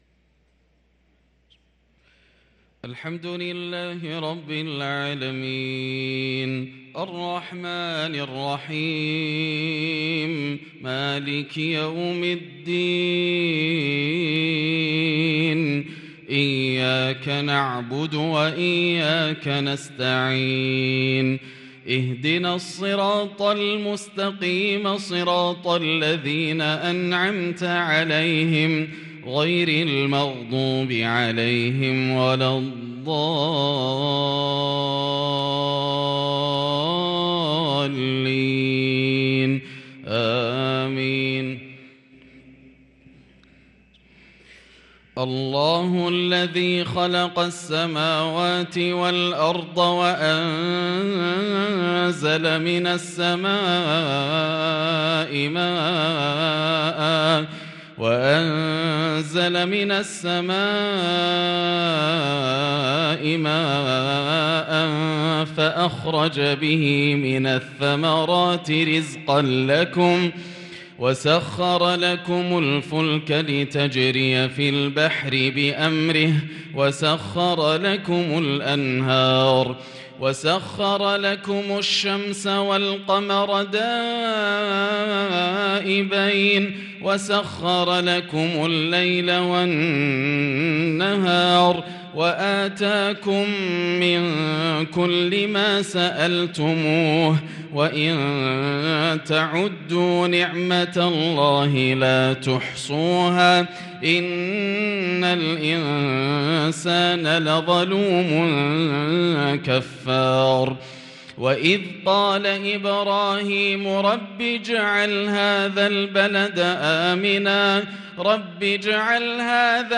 صلاة العشاء للقارئ ياسر الدوسري 4 جمادي الآخر 1444 هـ